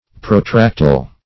Protractile \Pro*tract"ile\, a.